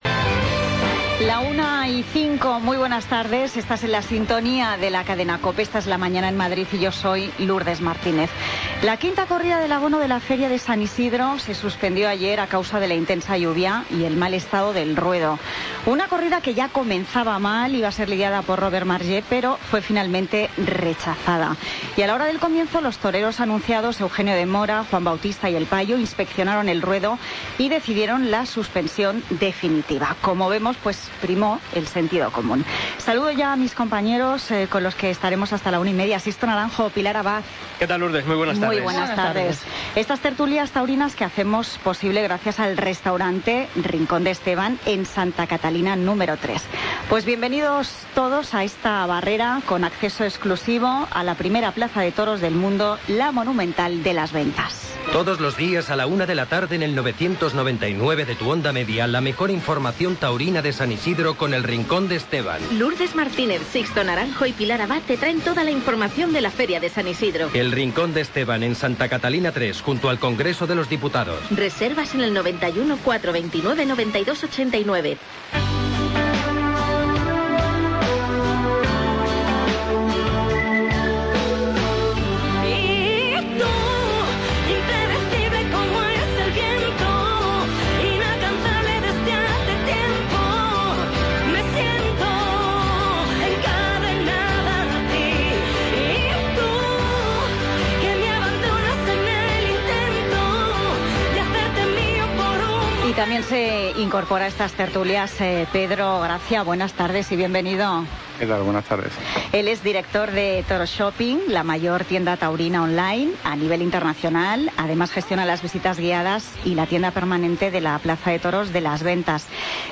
Tertulia Taurina Feria San Isidro COPE Madrid, miércoles 11 de mayo de 2016